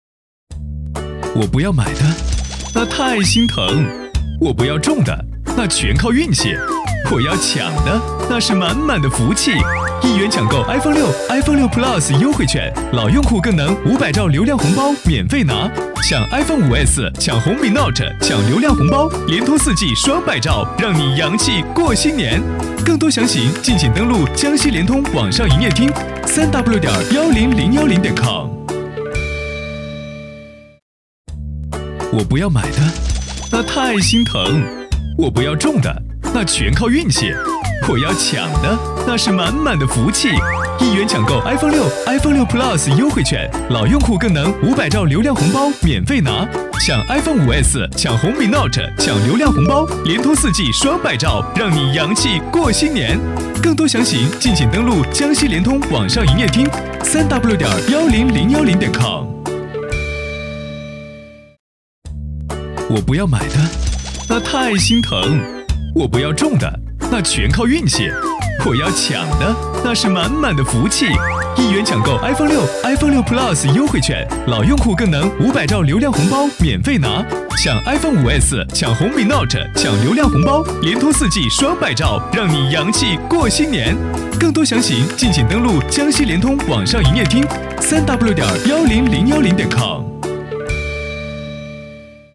国语青年低沉 、激情激昂 、大气浑厚磁性 、沉稳 、积极向上 、时尚活力 、男广告 、400元/条男S312 国语 男声 广告（独白 自述） 苏果 低沉|激情激昂|大气浑厚磁性|沉稳|积极向上|时尚活力